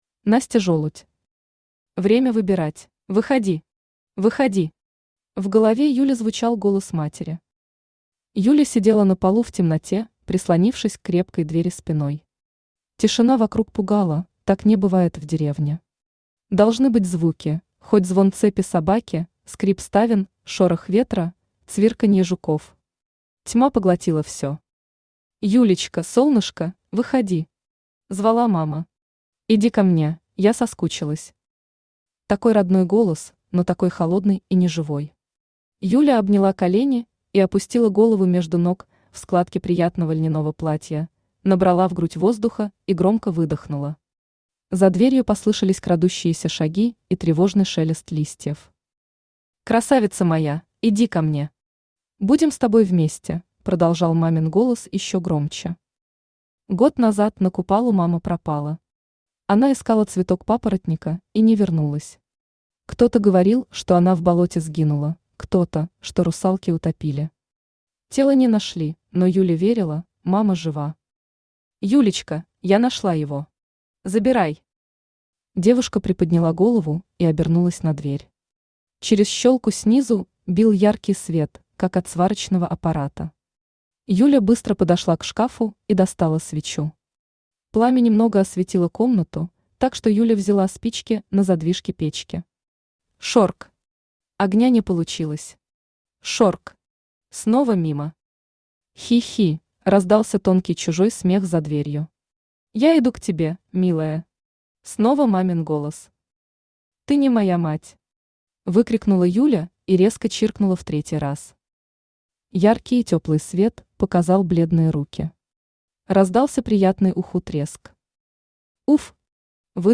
Aудиокнига Время выбирать Автор Настя Жолудь Читает аудиокнигу Авточтец ЛитРес.